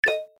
دانلود صدای اعلان خطر 3 از ساعد نیوز با لینک مستقیم و کیفیت بالا
جلوه های صوتی